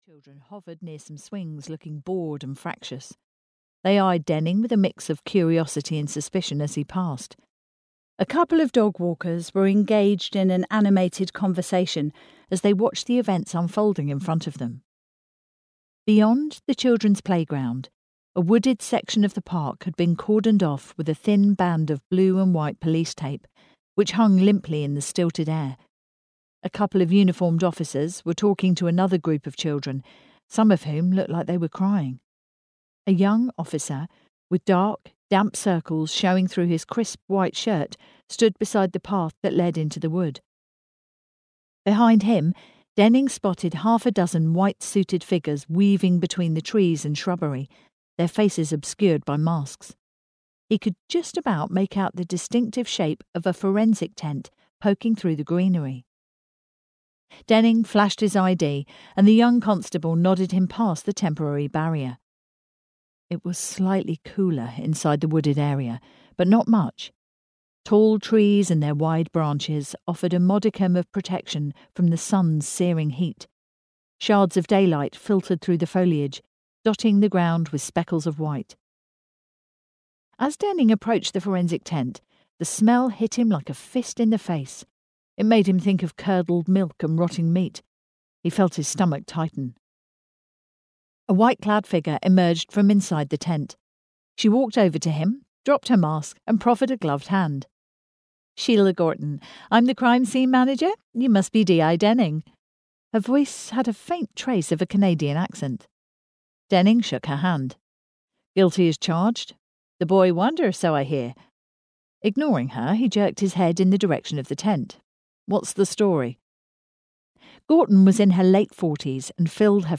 Know No Evil (EN) audiokniha
Ukázka z knihy